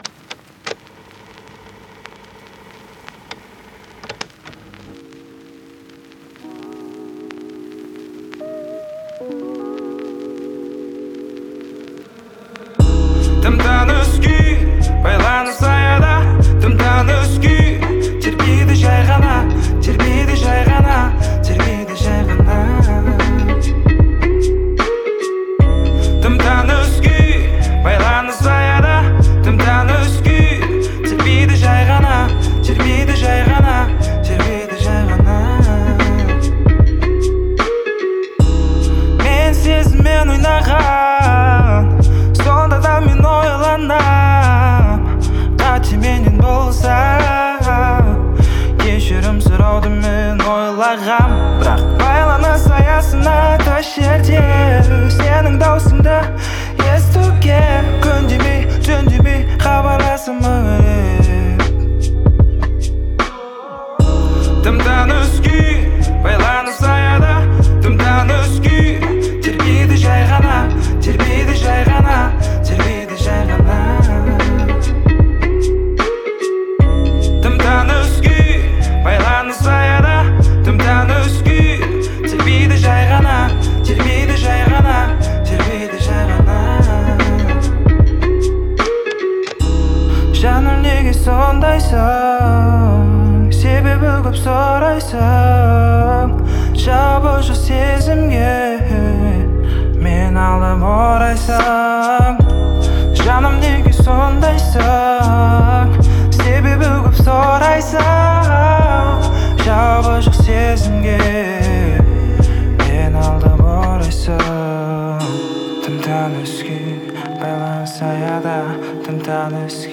это мощный трек в жанре хип-хоп